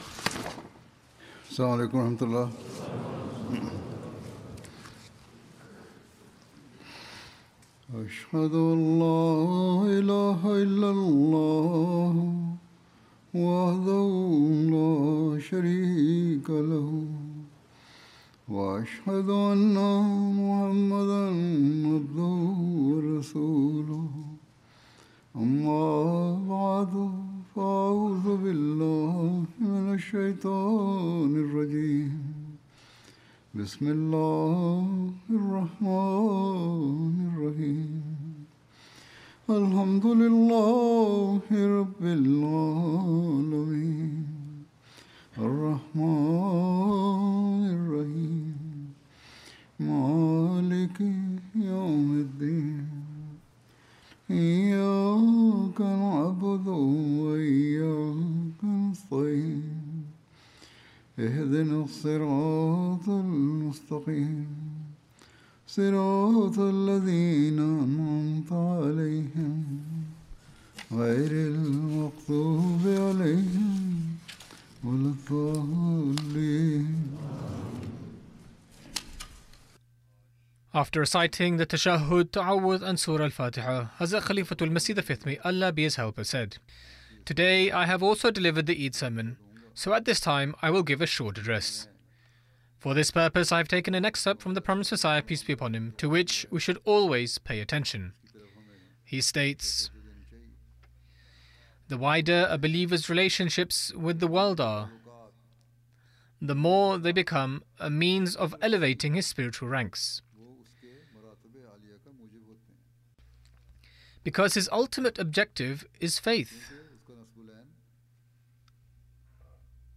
English translation of Friday Sermon delivered by Khalifa-tul-Masih on March 20th, 2026 (audio)